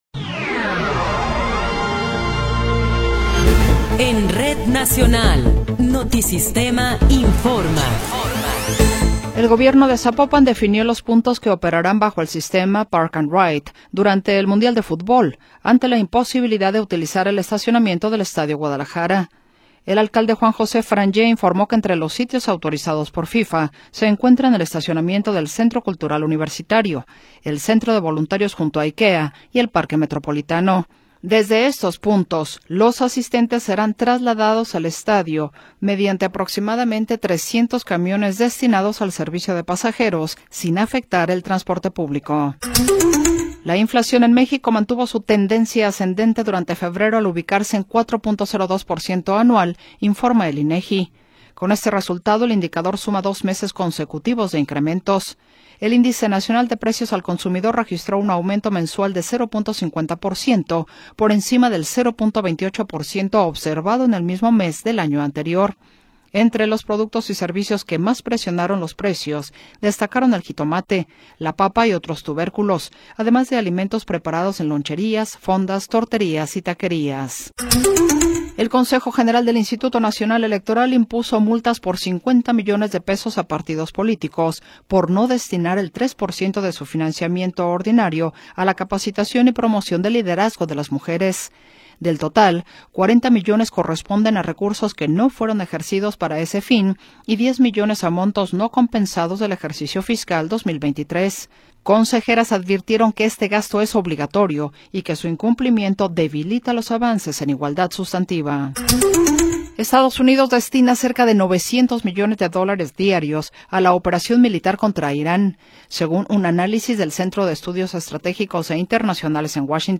Noticiero 15 hrs. – 14 de Marzo de 2026